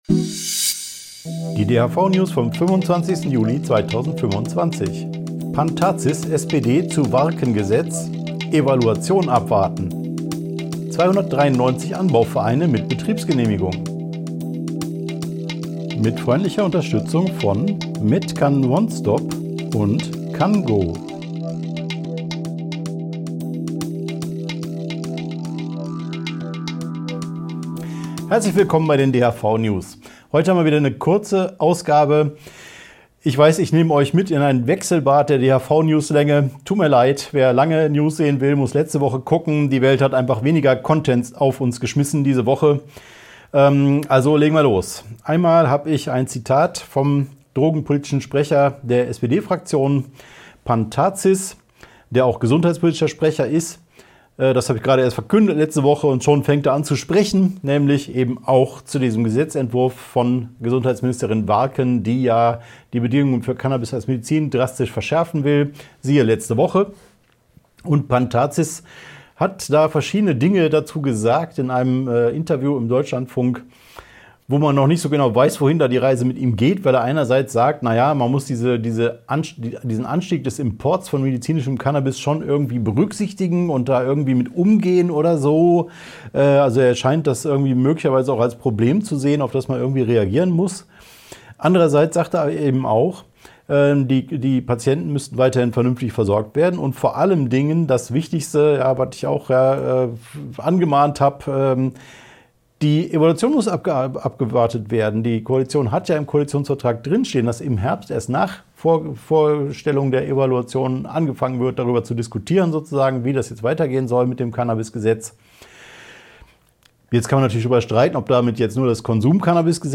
| DHV-News # 472 Die Hanfverband-Videonews vom 25.07.2025 Die Tonspur der Sendung steht als Audio-Podcast am Ende dieser Nachricht zum downloaden oder direkt hören zur Verfügung.